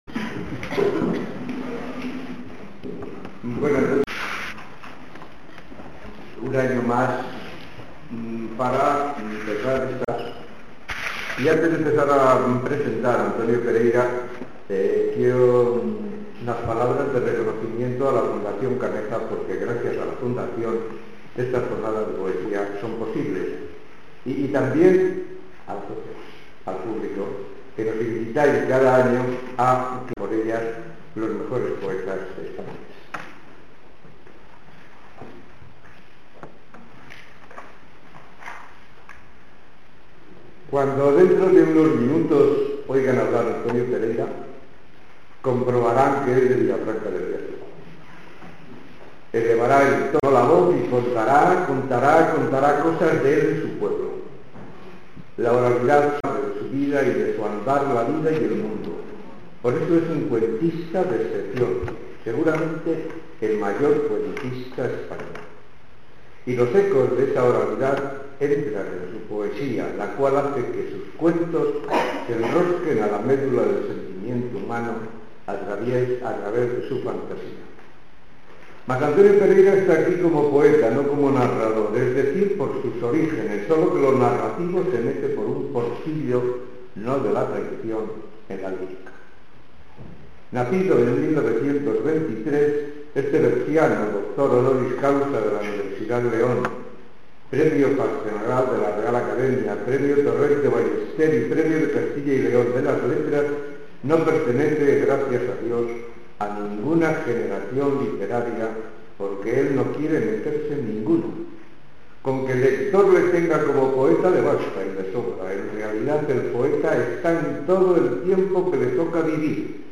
Recital de poesía en Palencia